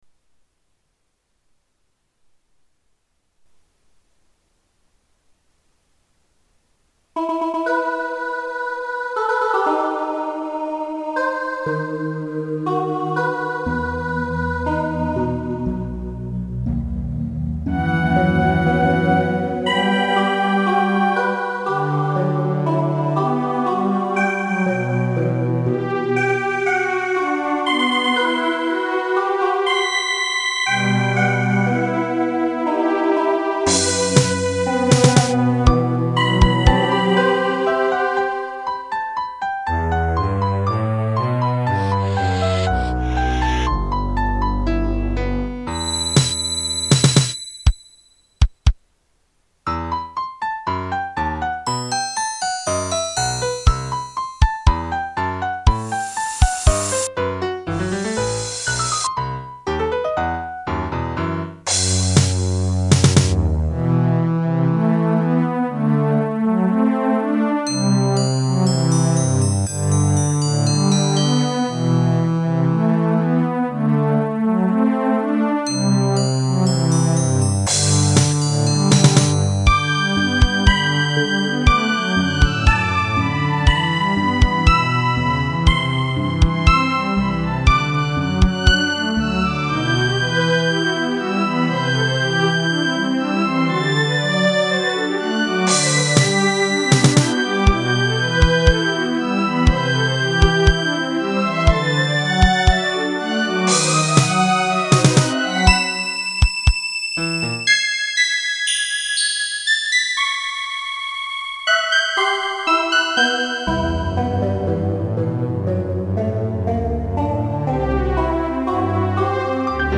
MIDとGuitarの曲